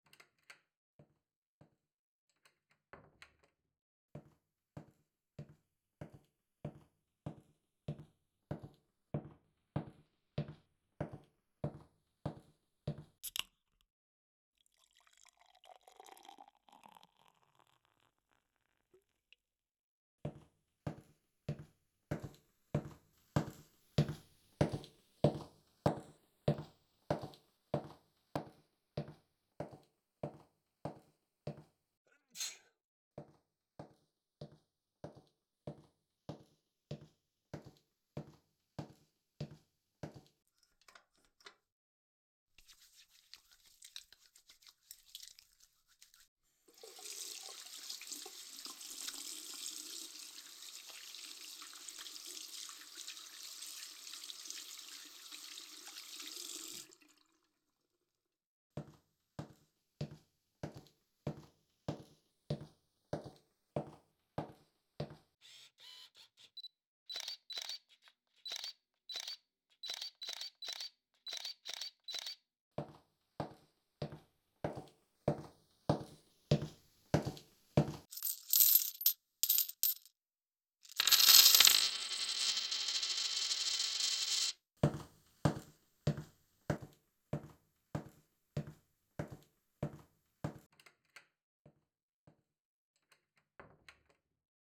Binaural Synthesis (Virtual 3D Audio) Samples: